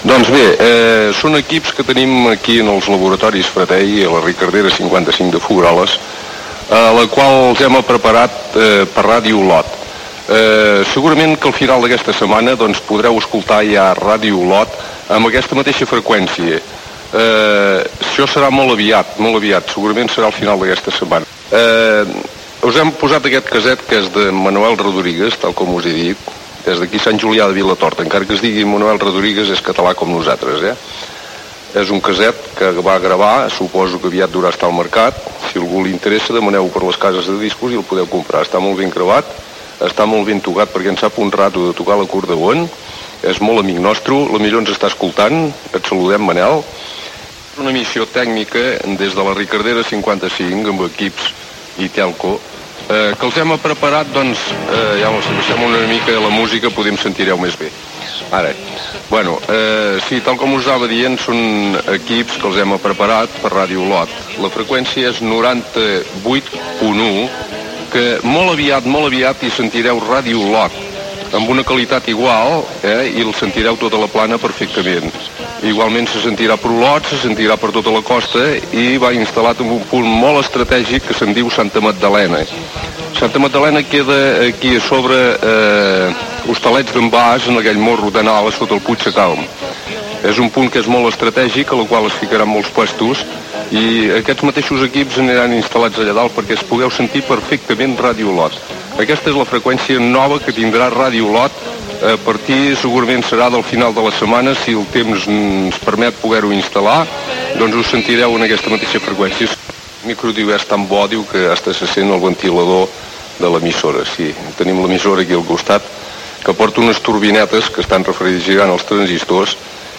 Emissió feta des de la seu de l'empresa FRATEI, a Folgueroles, quan preparava i provava el nou equip transmissor d'FM de Ràdio Olot. Es comenta les característiques del nou punt emissor i s'explica com és el lloc des d'on es fan les proves.